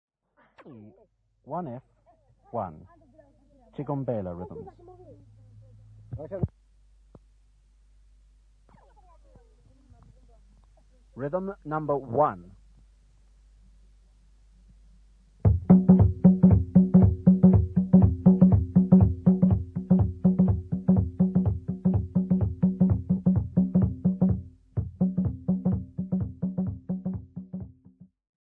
JBFT15-KV3-KV1F1-1.mp3 of Tshigombela rhythms